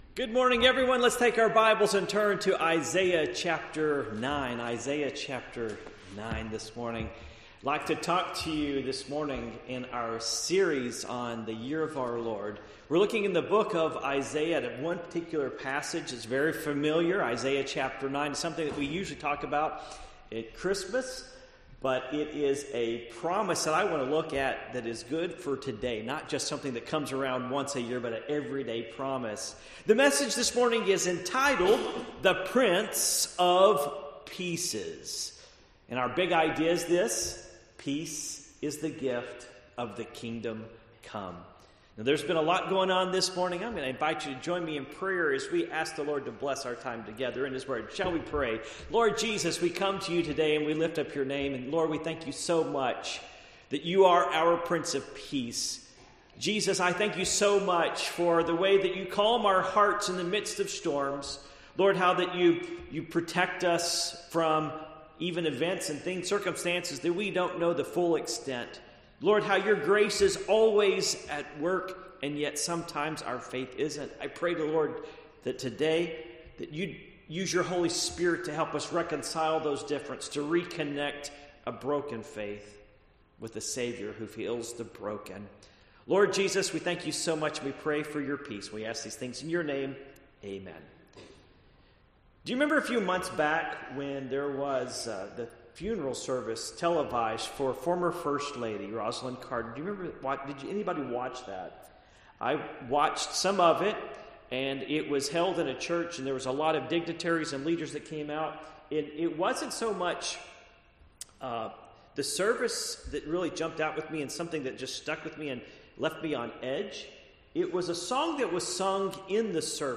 Passage: Isaiah 9:6-7 Service Type: Morning Worship